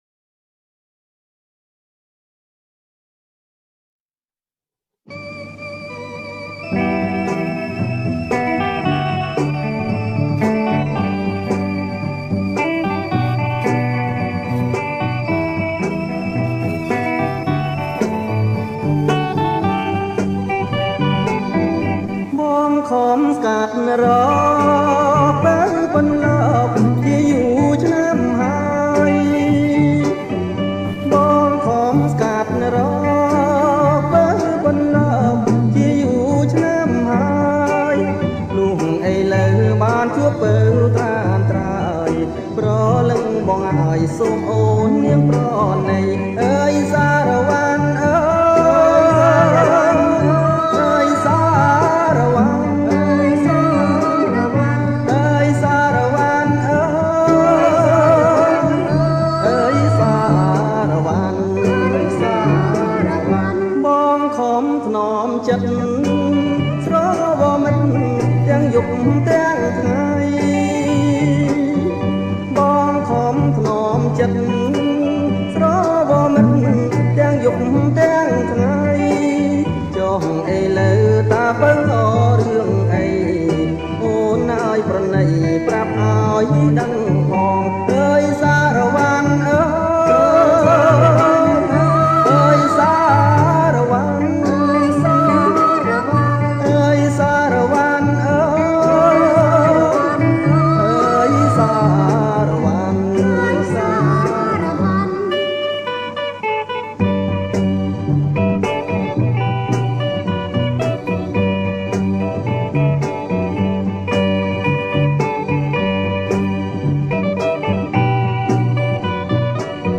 • ប្រគំជាចង្វាក់ សារ៉ាវ៉ាន់
ថតផ្ទាល់ពីថាស  Vinyl